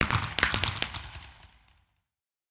twinkle_far.mp3